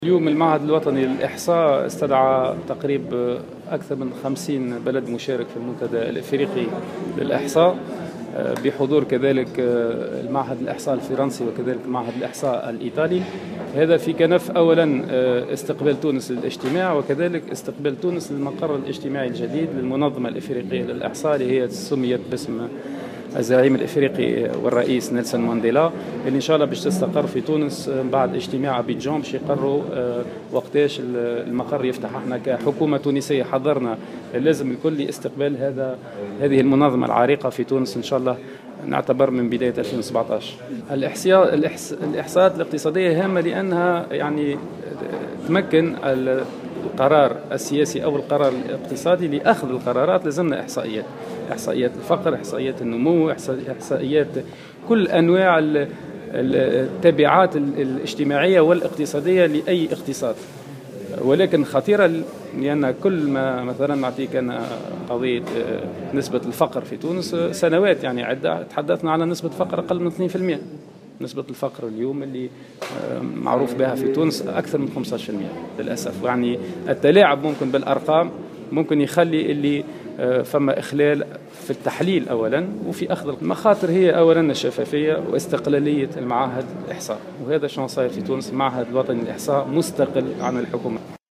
أكد فاضل عبد الكافي وزير الاستثمار والتعاون الدولي على هامش اشرافه اليوم الأربعاء 2 نوفمبر 2016 على افتتاح المؤتمر الإفريقي الثاني عشر لتنمية الإحصاء بإفريقيا أن هذا الاجتماعي يجري بمشاركة أكثر من 50 بلد وحضور معهد الإحصاء الفرنسي والإيطالي .